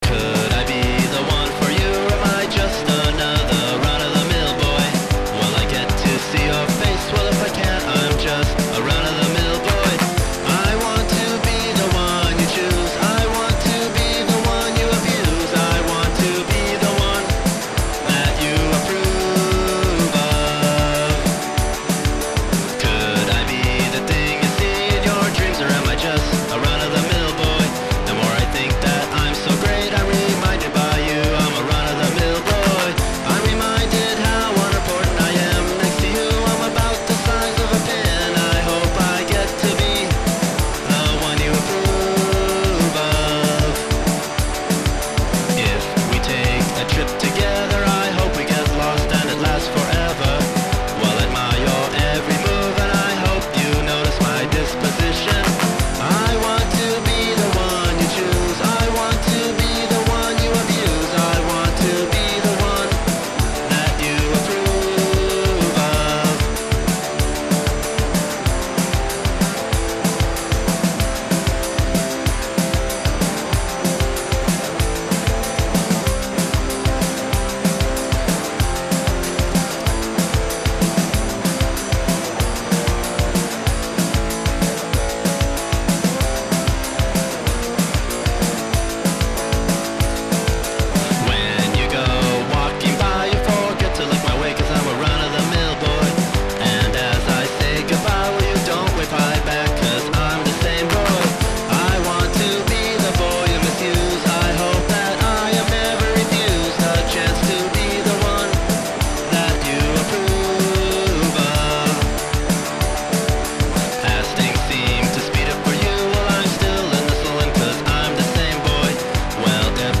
fuzz n' handclap boy pop